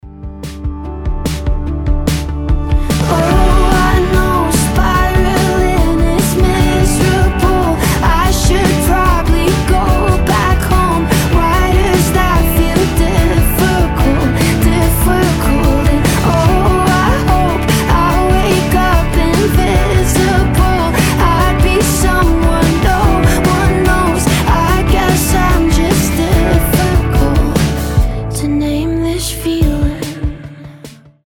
• Качество: 320, Stereo
приятные
красивый женский голос
lo-fi